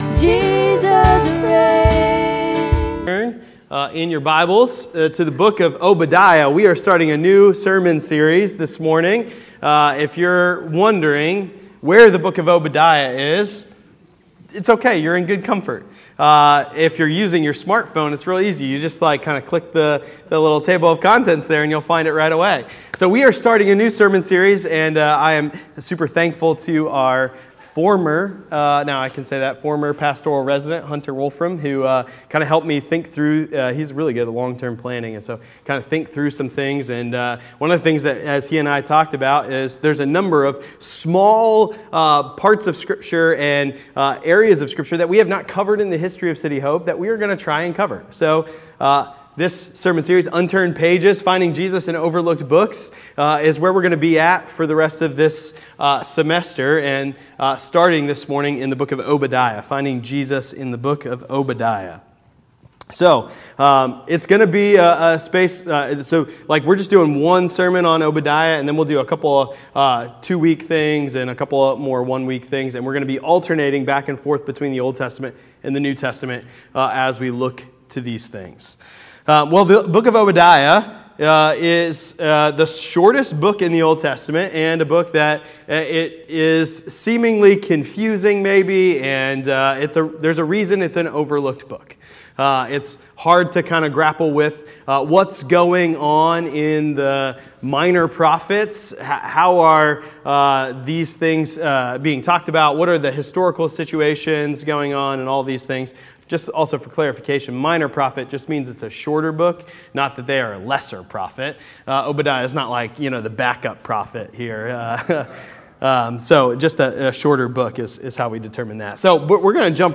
Sermons by City Hope Fellowship